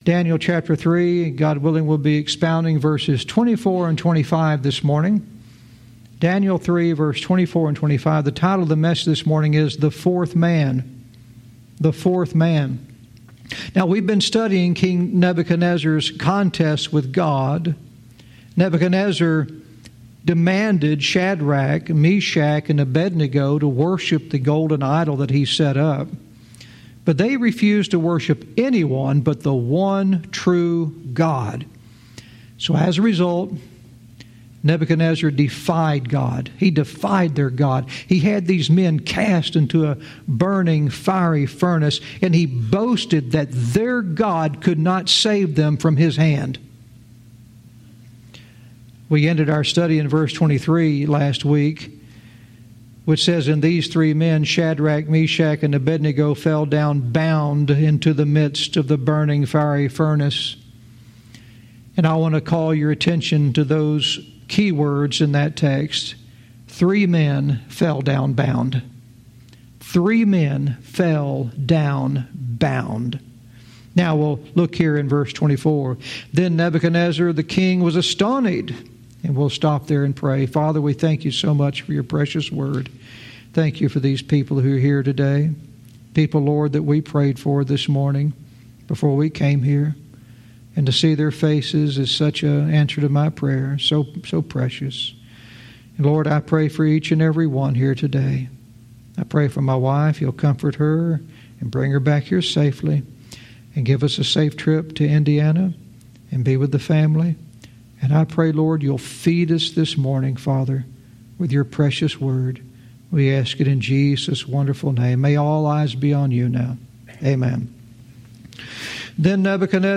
Verse by verse teaching - Daniel 3:24-25 "The Fourth Man"